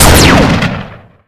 gun1.ogg